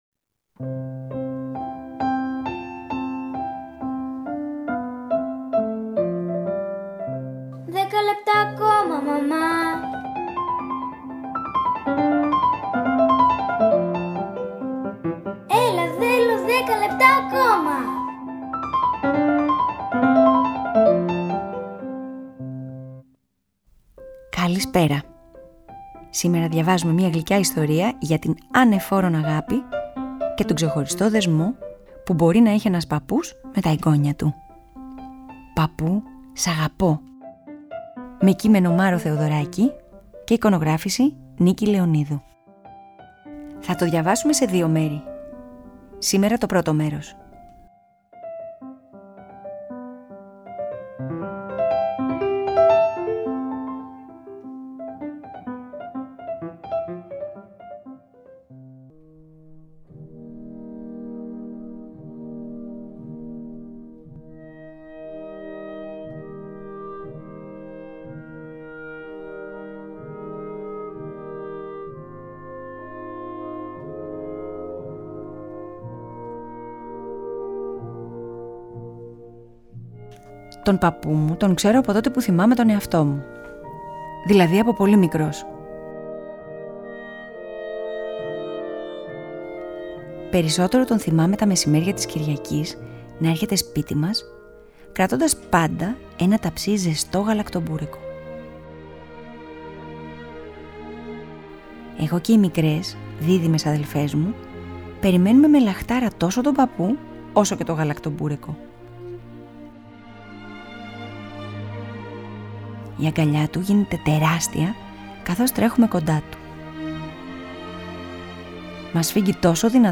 Louise Farrenc, Symphony No3 in G Minor